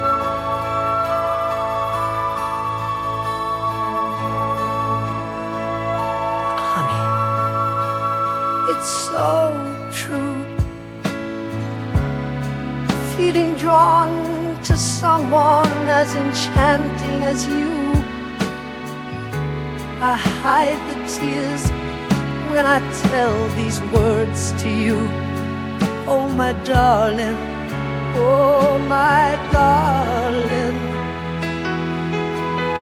Rivers B Band, a Banda Virtual.
Ao ouvir você percebe a influência Rock Ballad que dominava as rádios dos anos 1950 a 1960.